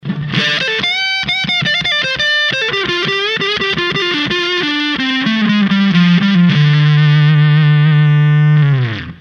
で、いつもの安いトランジスタアンプで音出しです。